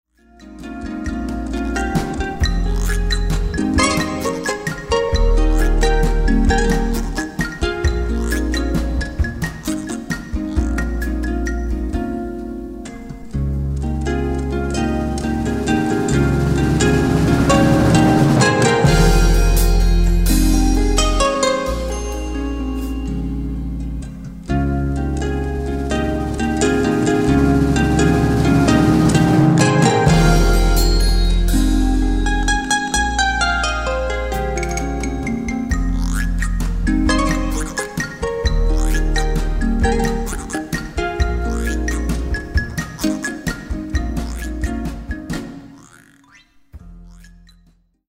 Live recordings from: